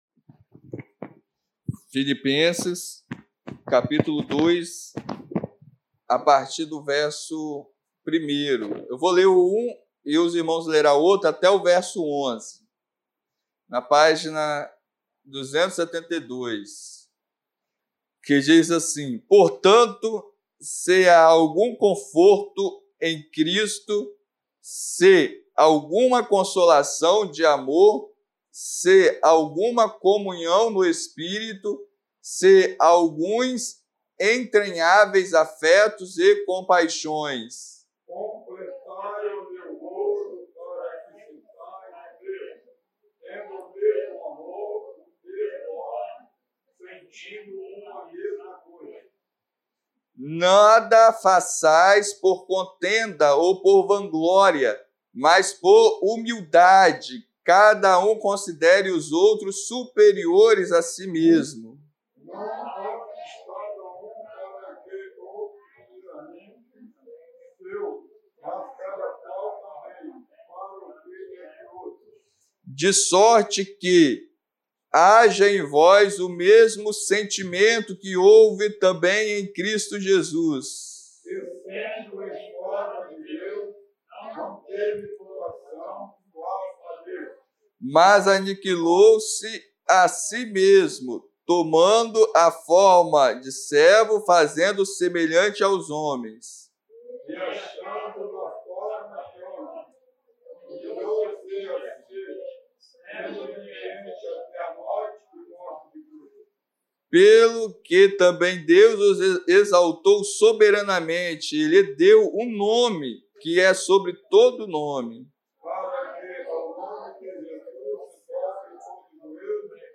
Ceia do Senhor